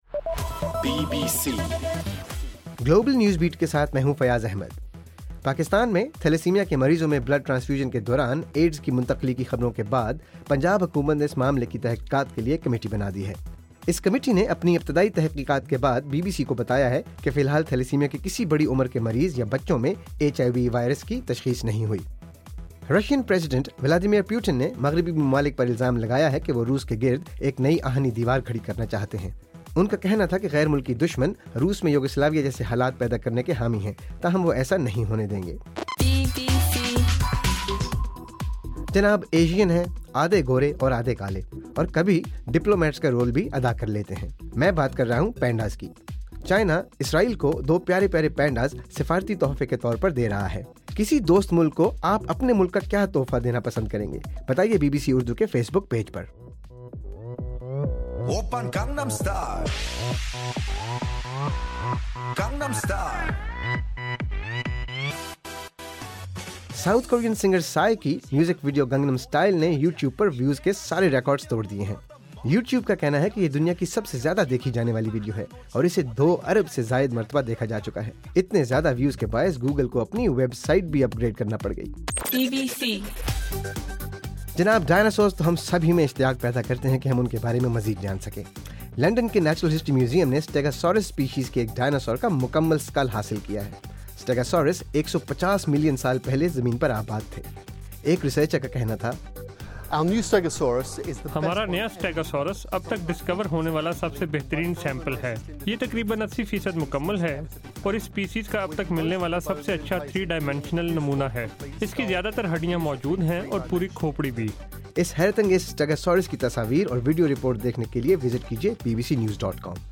دسمبر 5: صبح 1 بجے کا گلوبل نیوز بیٹ بُلیٹن